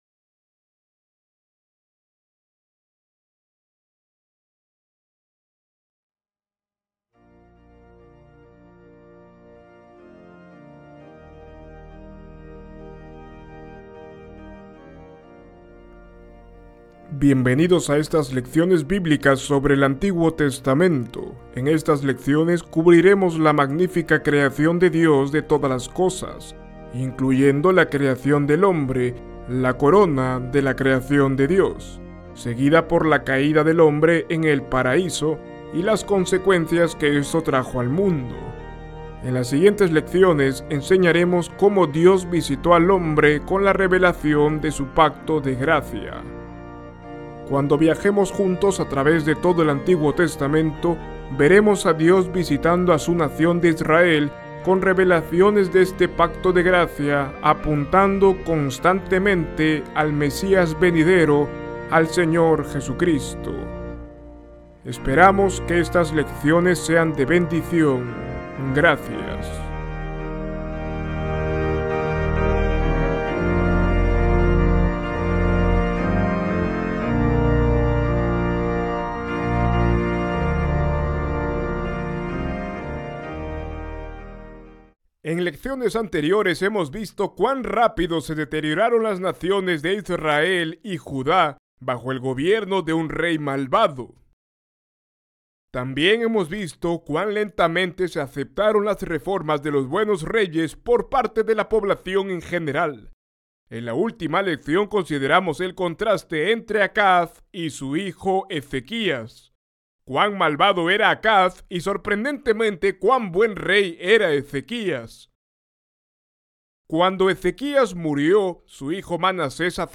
En esta lección veremos la maravillosa historia de su conversión. Ver video Descargar video MP4 Escuchar lección Descargar audio en mp3 Ver transcripción en PDF Descargar transcripción en PDF Guia de Estudio